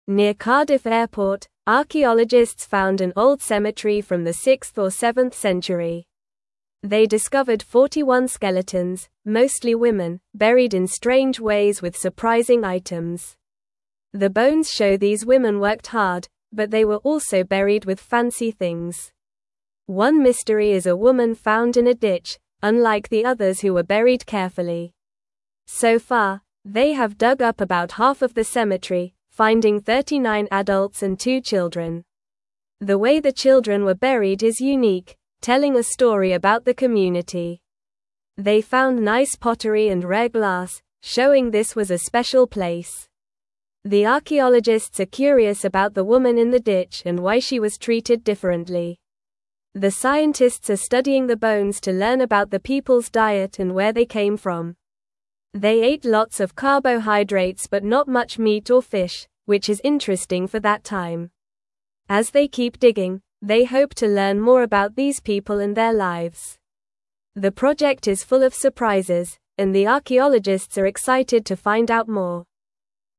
Normal
English-Newsroom-Lower-Intermediate-NORMAL-Reading-Old-Cemetery-Found-Near-Cardiff-Airport.mp3